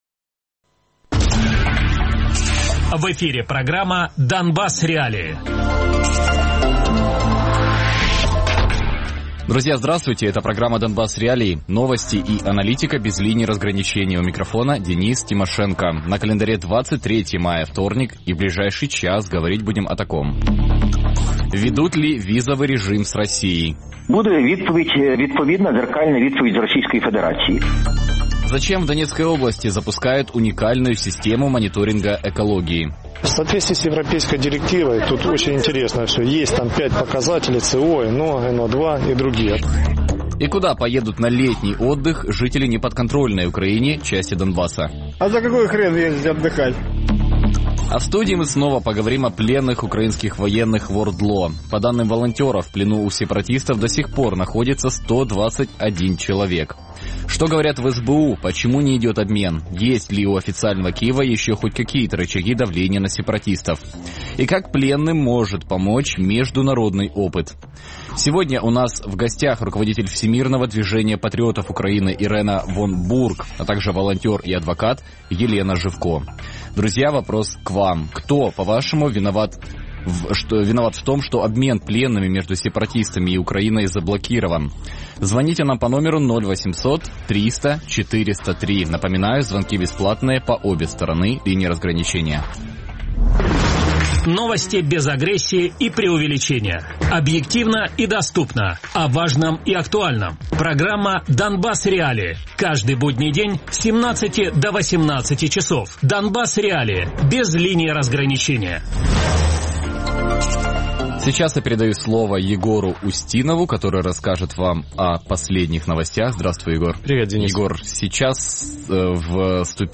адвокат Радіопрограма «Донбас.Реалії» - у будні з 17:00 до 18:00.